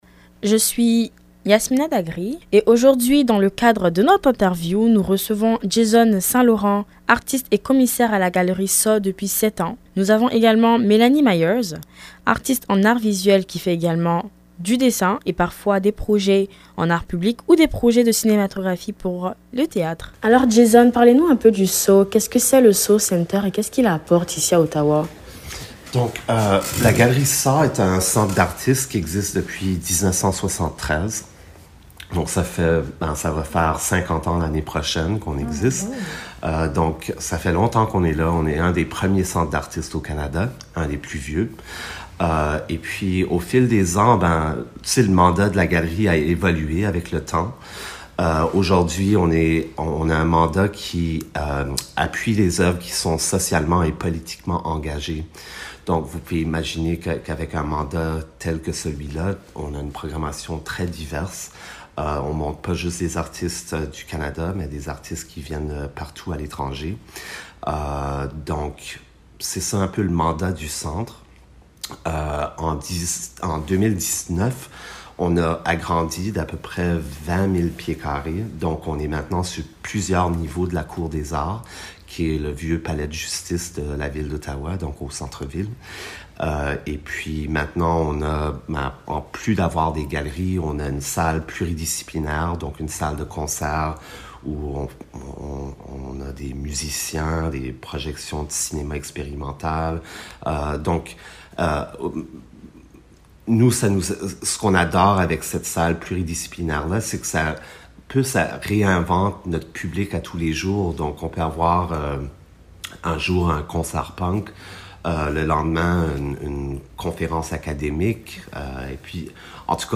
Entrevue-SAW-CENTRE.mp3